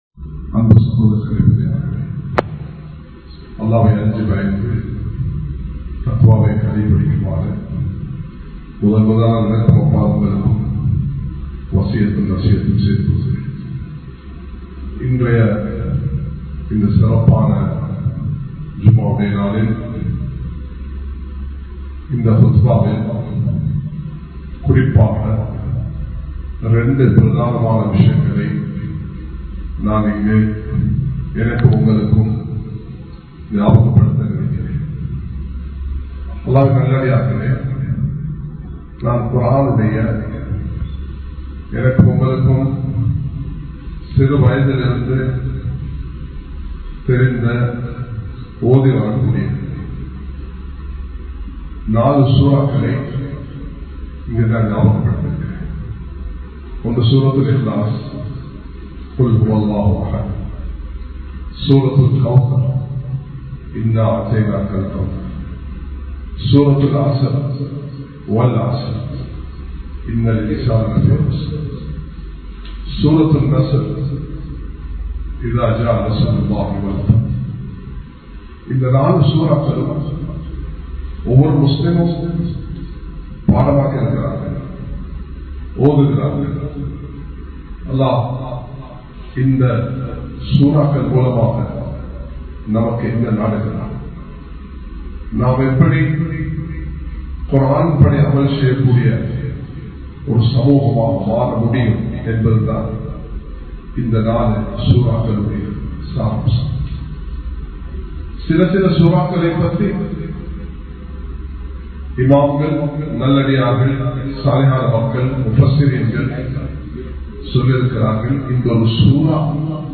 04 சூராக்கள் சொல்லும் செய்தி | Audio Bayans | All Ceylon Muslim Youth Community | Addalaichenai
Colombo 06, Mayura Place, Muhiyadeen Jumua Masjith